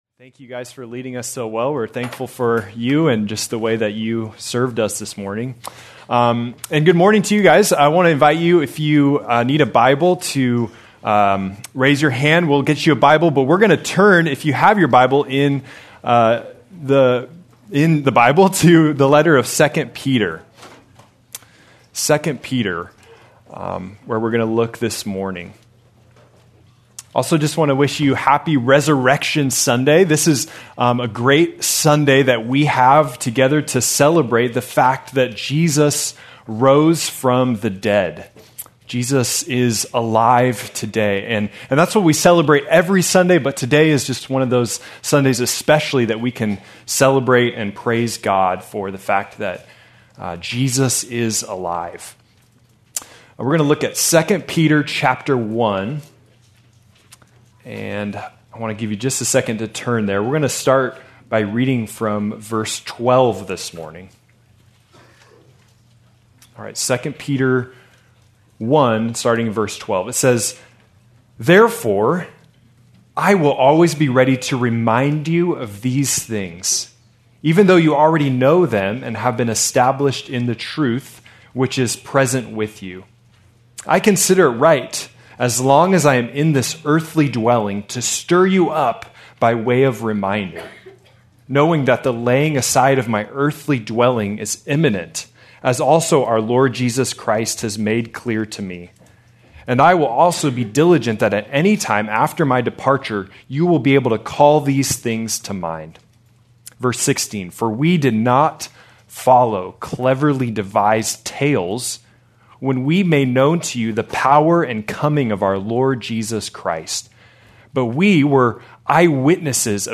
April 5, 2026 - Sermon